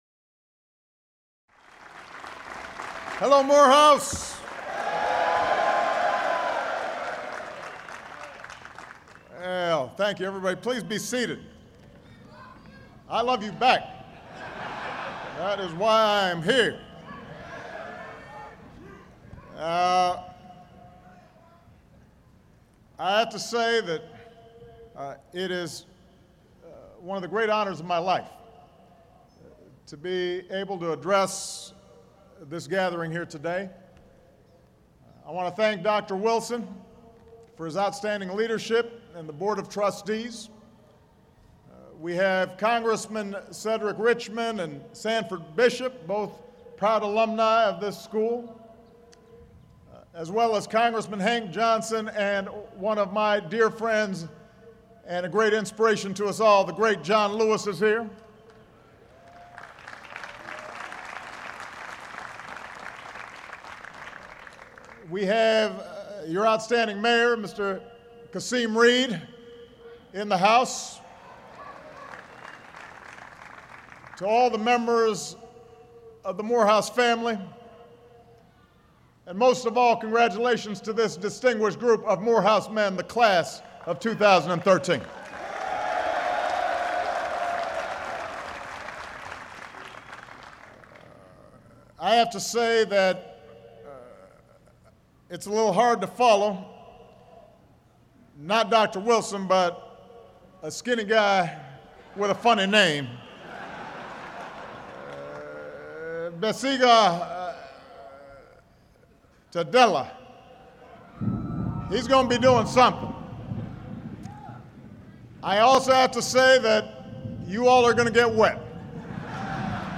U.S. President Barack Obama delivers the commencement address to the 2013 graduates of Morehouse College in Atlanta, GA